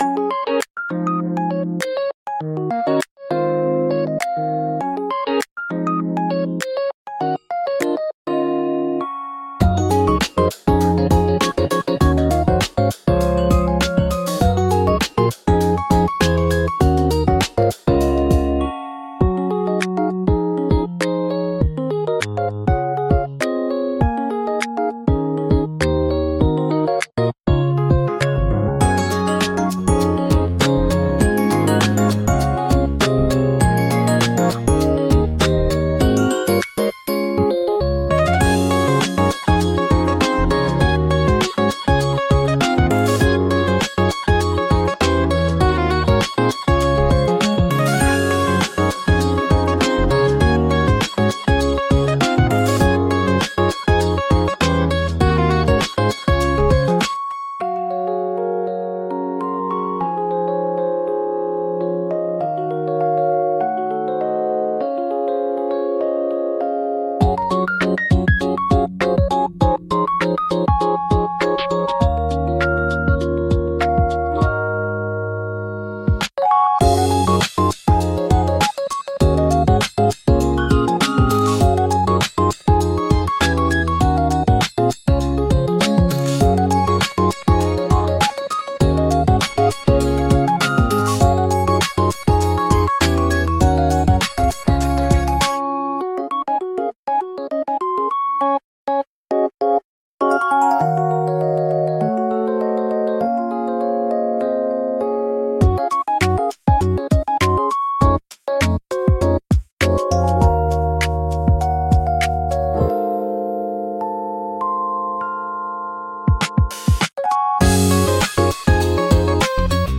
穏やかで可愛い、はじまりのBGM